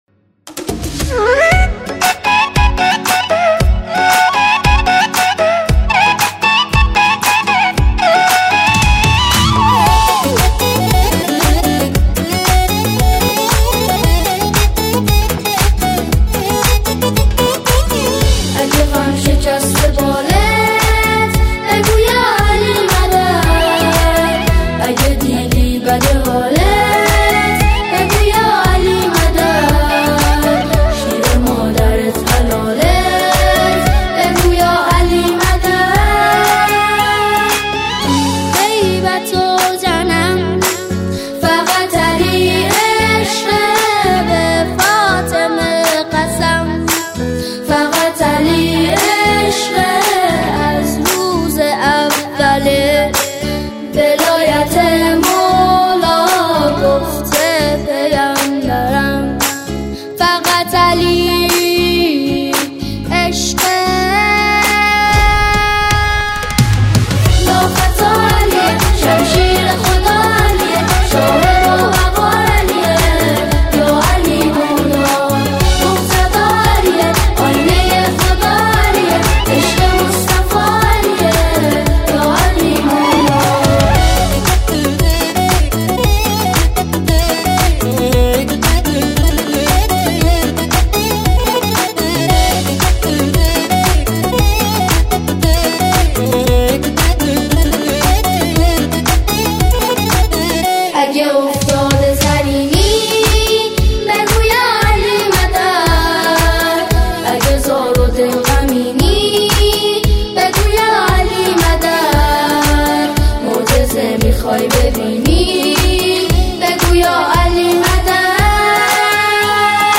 قطعه‌ای شاد است
به مناسبت “عید غدیر خم”